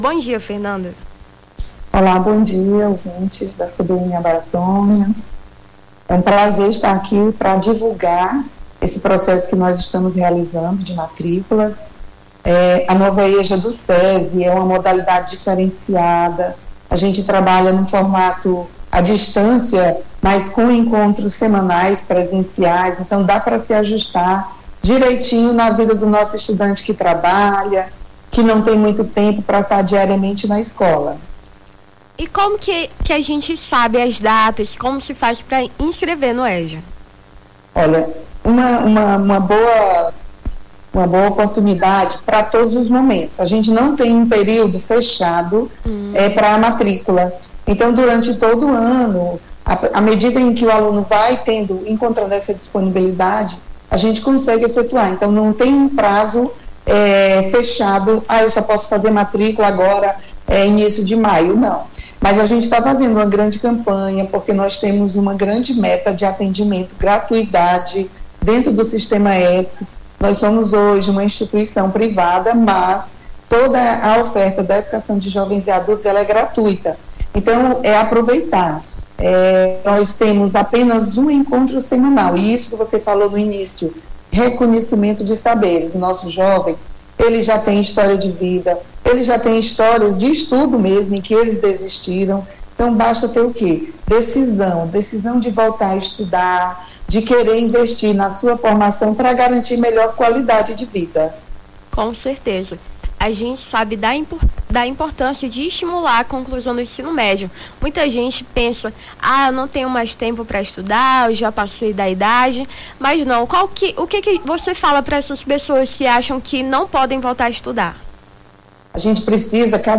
Nome do Artista - CENSURA - ENTREVISTA (ENSINO EJA DIVULGAÇÃO) 28-04-23.mp3